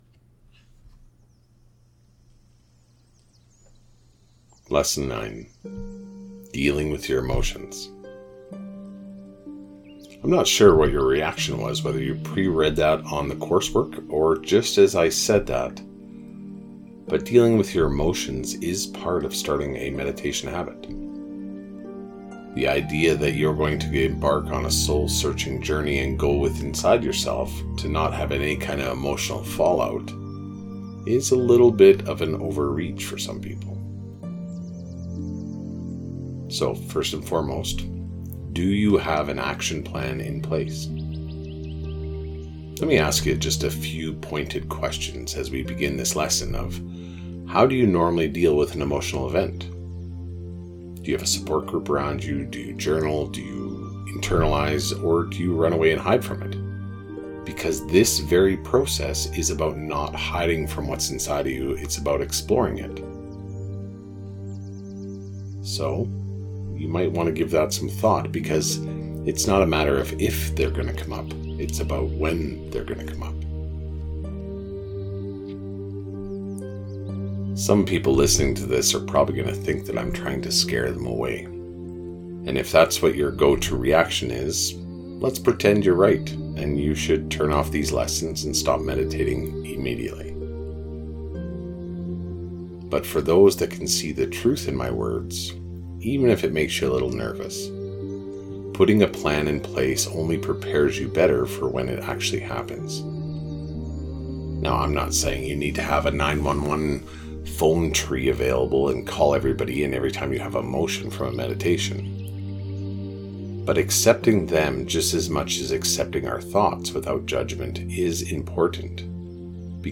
Meditation-Lesson-9.mp3